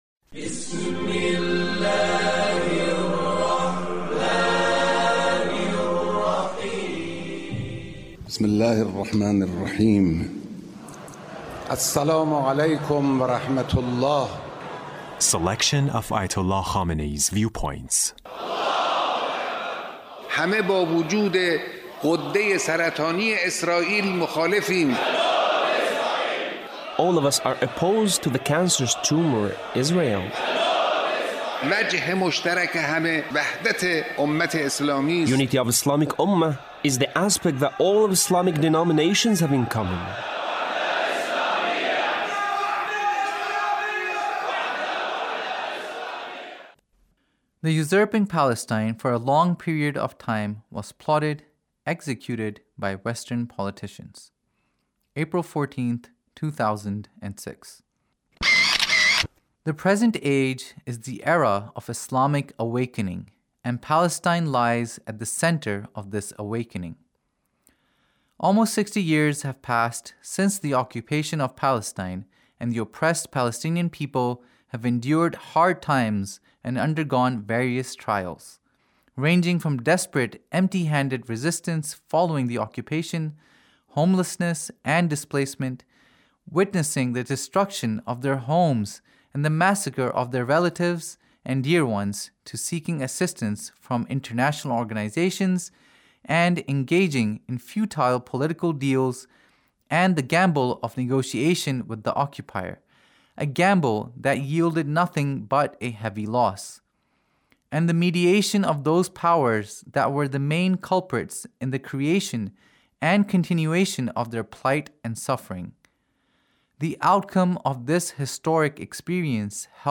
Leader's Speech (1881)